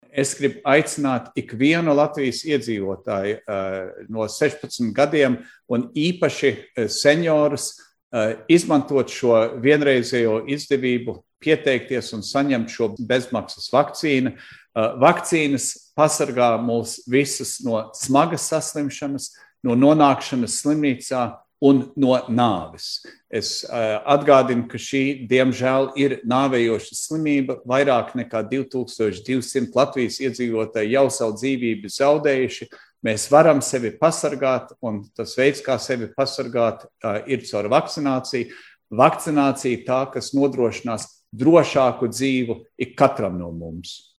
Plašāk Ministru prezidents Krišjānis Kariņš.